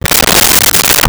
Dresser Drawer Open 03
Dresser Drawer Open 03.wav